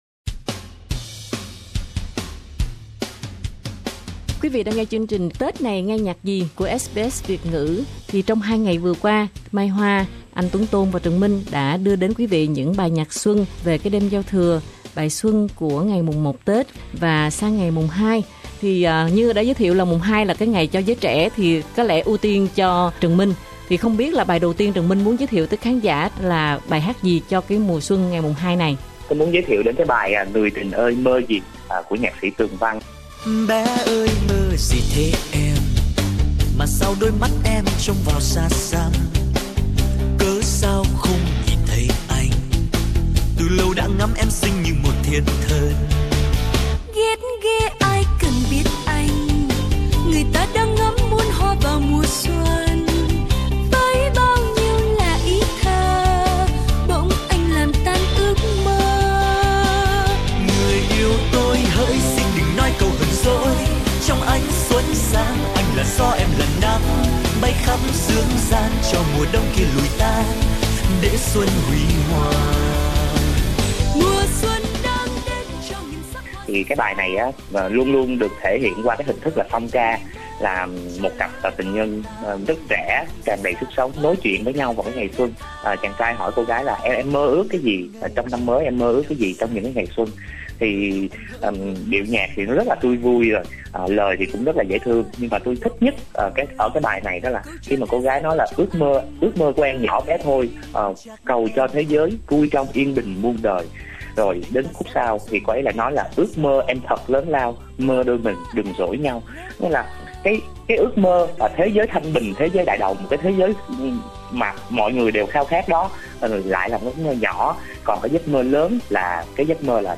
Mùng Hai Tết với các bài nhạc xuân vui tươi sôi nổi mang màu sắc tuổi trẻ nhưng cũng không kém phần mơ màng và đầy trải nghiệm của các nghệ sĩ đang vào độ xuân sắc của sáng tác.